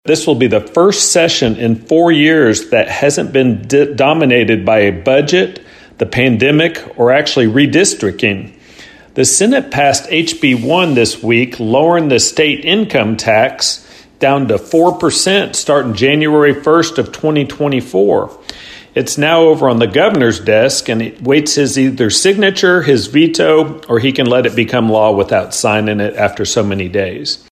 click to download audioEighth District State Representative Walker Thomas says the legislation is a needed step to keep Kentucky moving in the right direction.